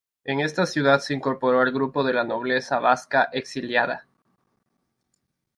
Pronounced as (IPA) /ˈbaska/